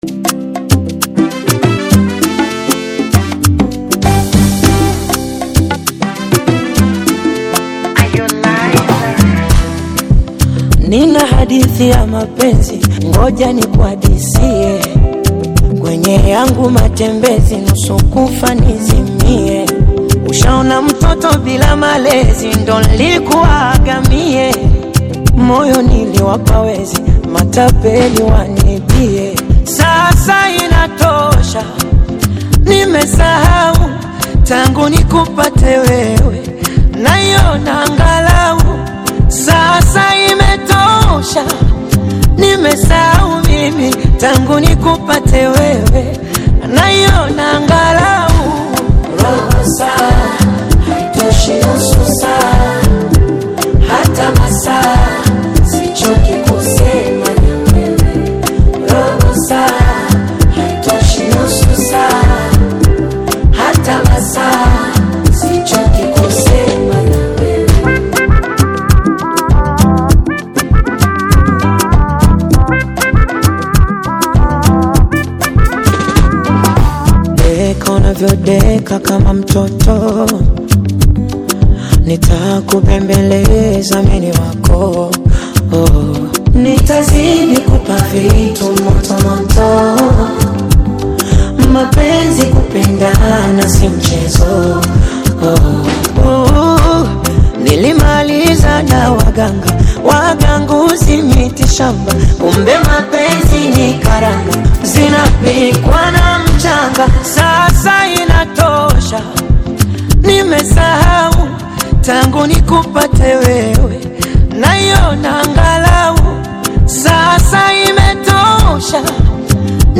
BONGO FLAVOUR